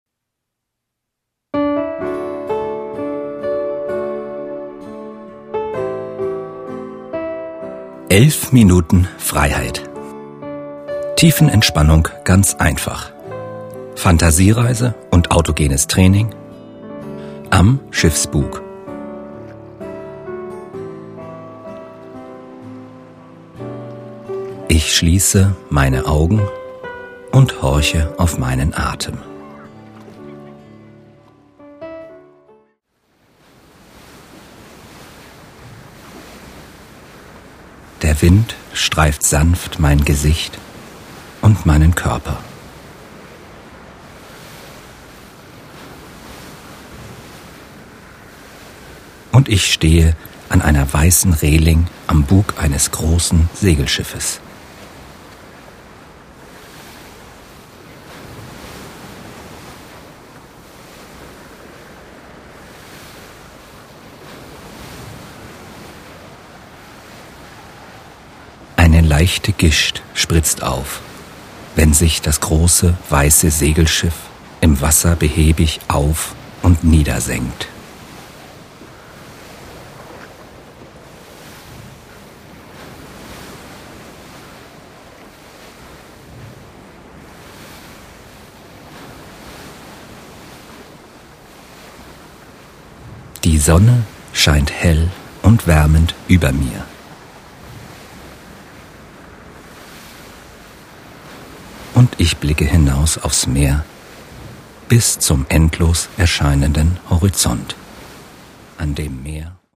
männliche Stimme   11:00 min